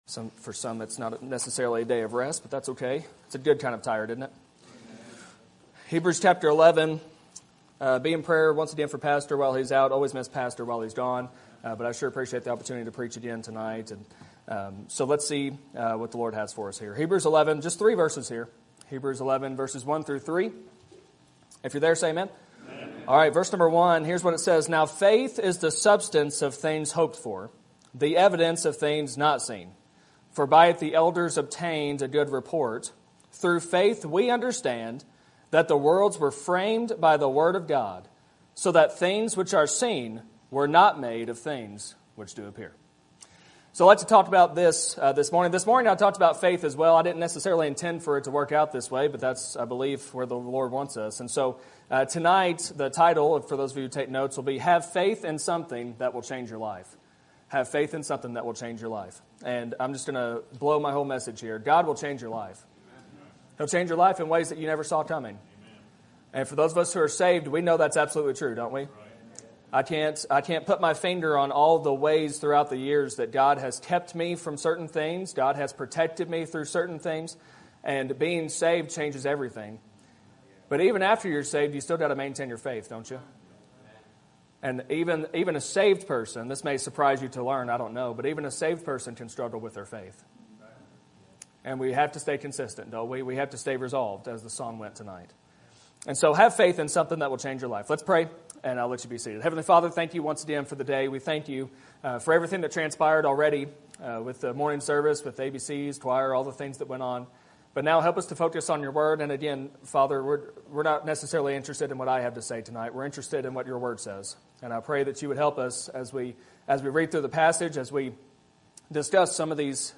Sermon Topic: General Sermon Type: Service Sermon Audio: Sermon download: Download (22.53 MB) Sermon Tags: Hebrews Faith God Word